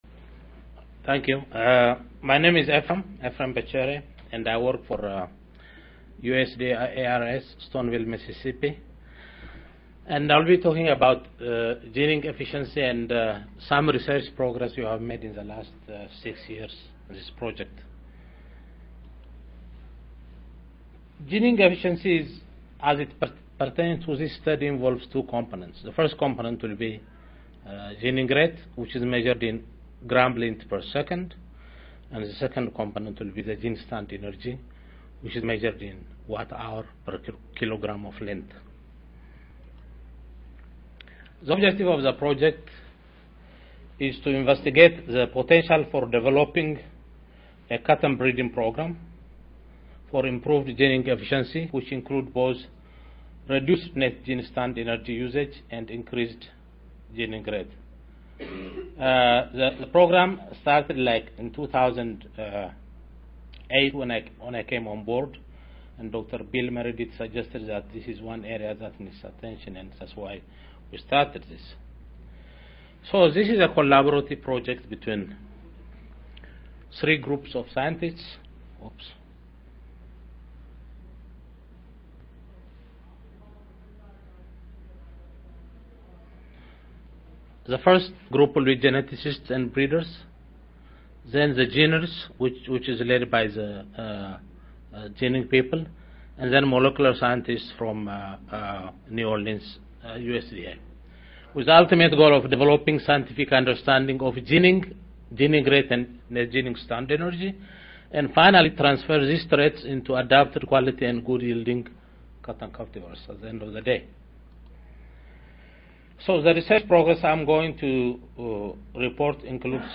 USDA-ARS-SRRC Audio File Recorded Presentation